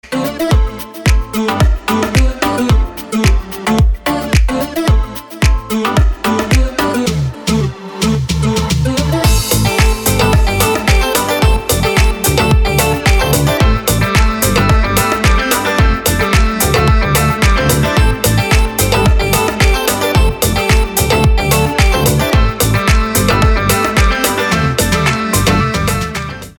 • Качество: 320, Stereo
громкие
dance
без слов